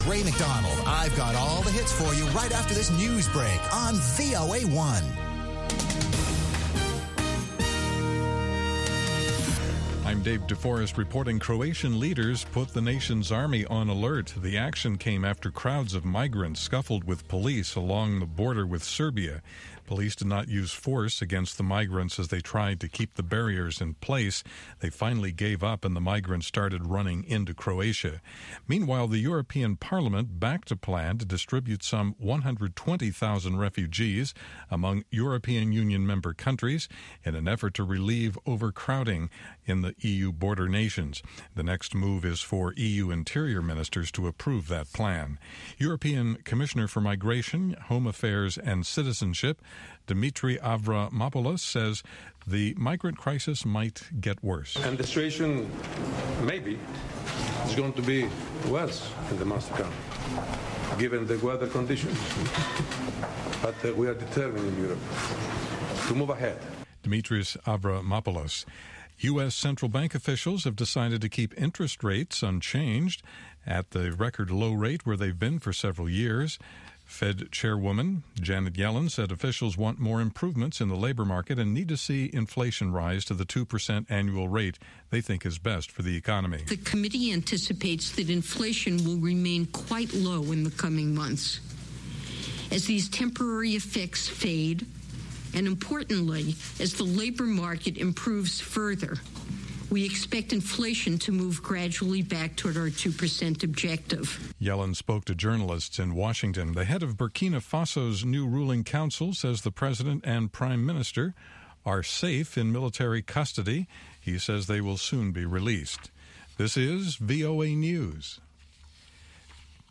from N’dombolo to Benga to African Hip Hop
pan-African music